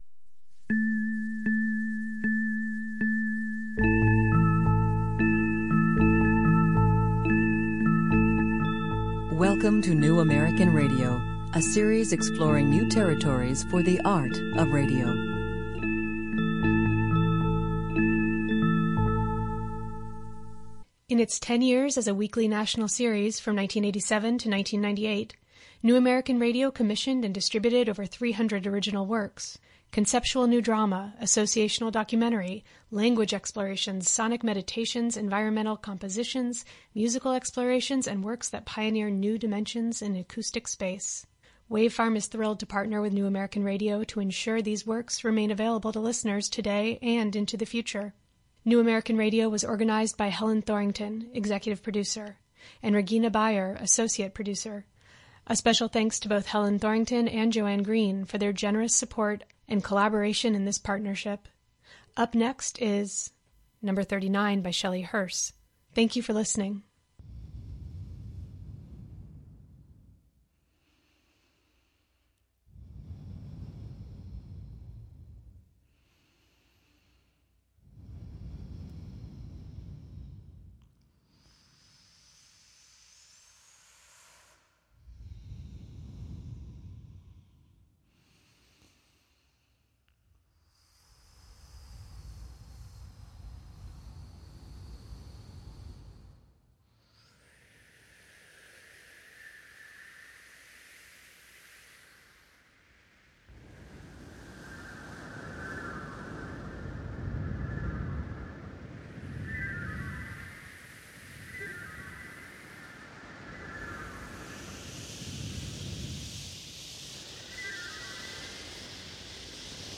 spoken and sung
sampling keyboard